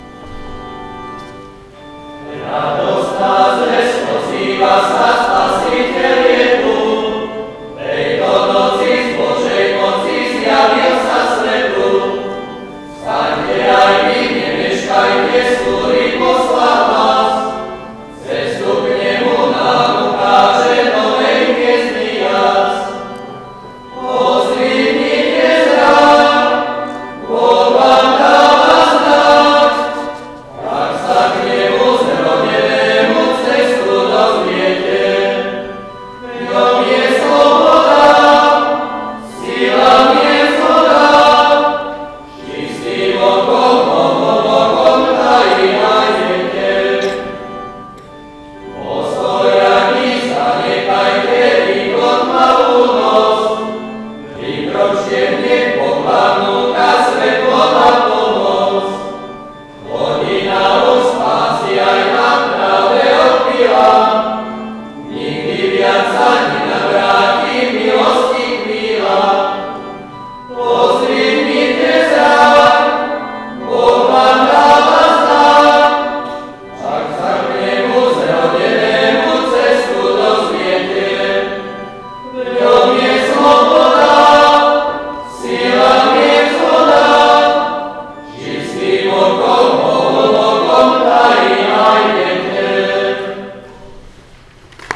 Trojkráľový koncert.
Atmosféra bola príjemná.
Nahrávky zvuku boli robené bežným diktafónom, preto ich kvalita nie je veľmi dobrá.
Mužský zbor z Kaplnej
Mužský-zbor-z-Kaplnej.wav